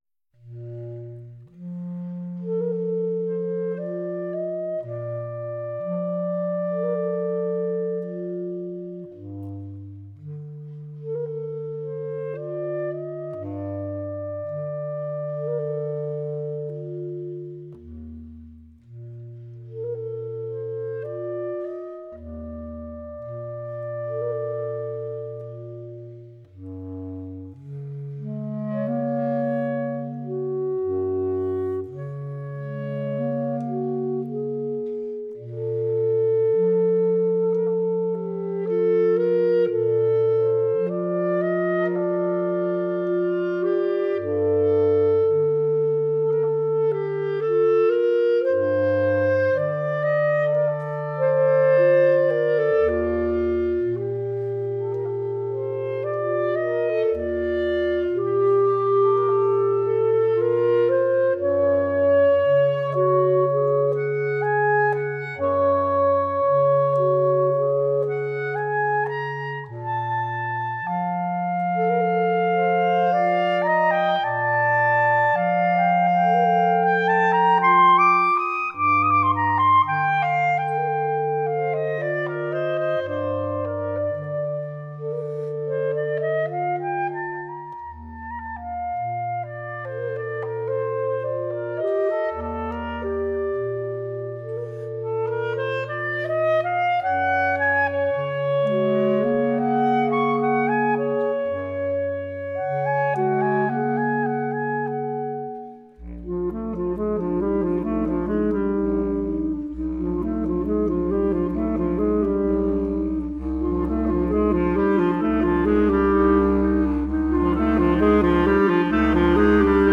Klarinettenquartett